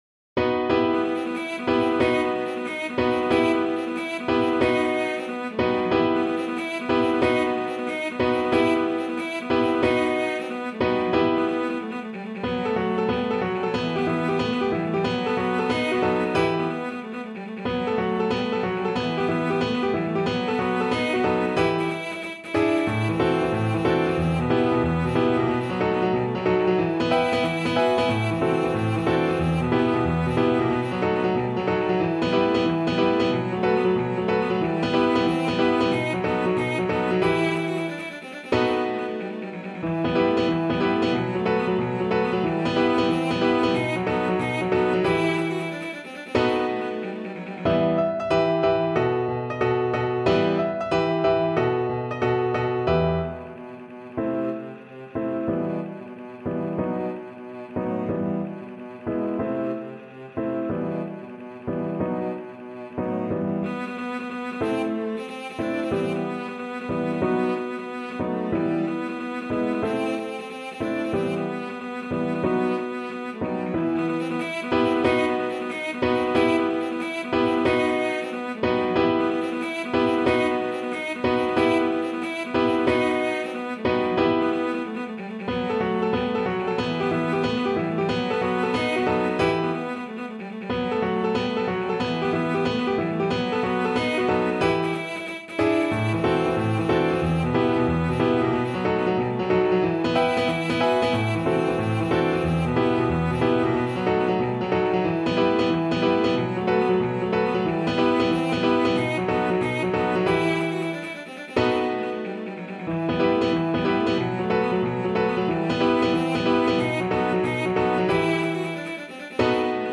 Cello
Pizzica music is known for its fast-paced rhythms, intricate melodies, and infectious energy.
E minor (Sounding Pitch) (View more E minor Music for Cello )
Molto allegro .=c.184
E3-F#5
6/8 (View more 6/8 Music)
Classical (View more Classical Cello Music)